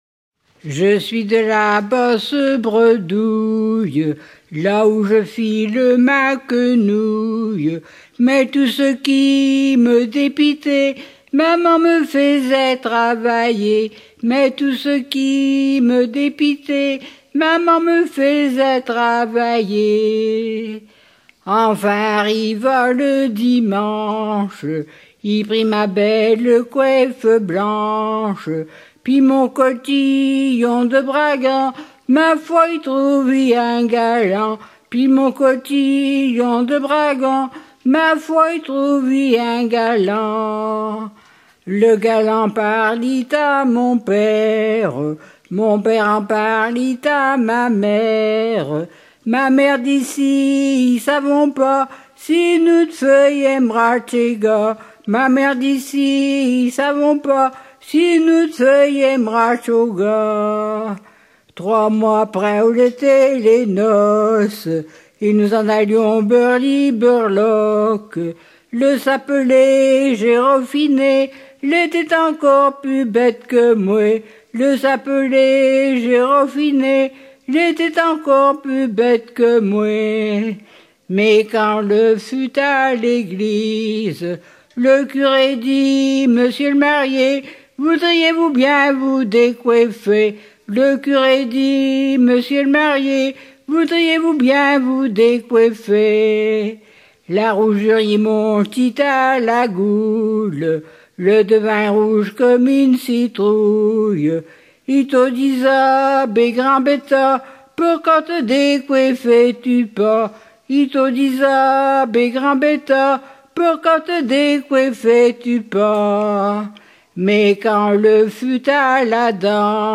Genre strophique